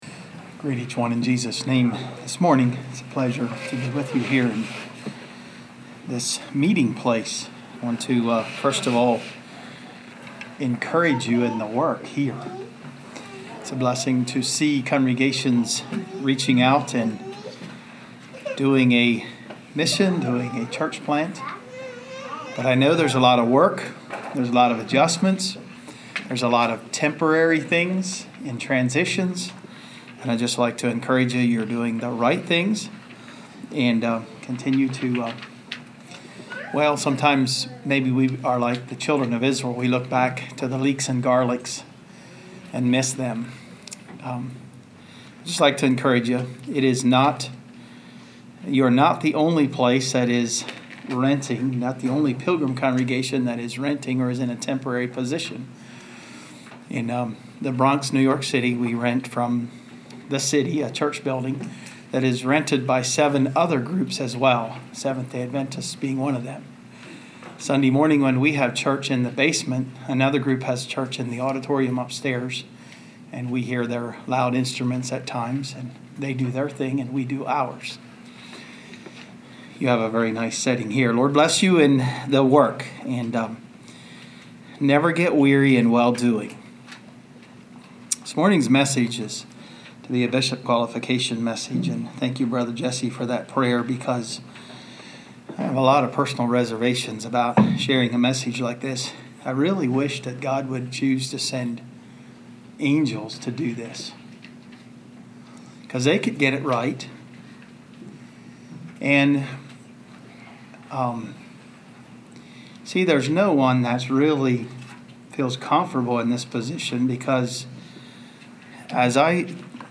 Bishop qualification sermon that explains the role of the bishop. While it focuses on the Bishop's role in the church, everything should be applied by all of us.